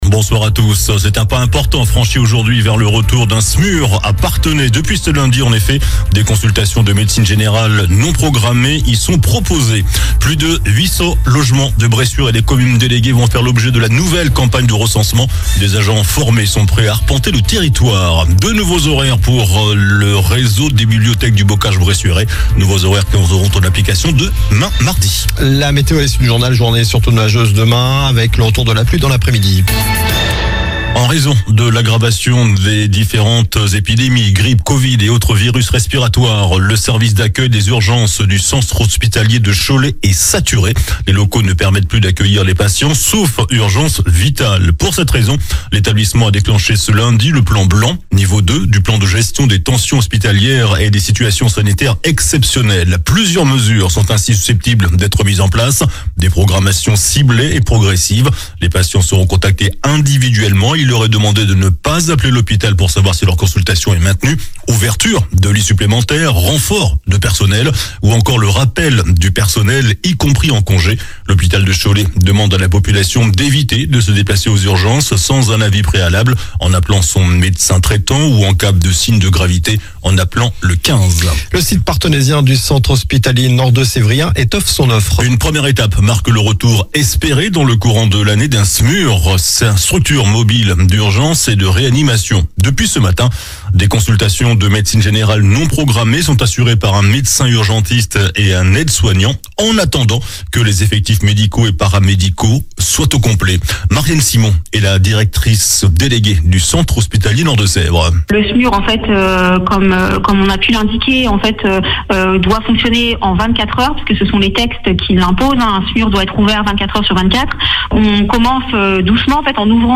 JOURNAL DU LUNDI 06 JANVIER ( SOIR )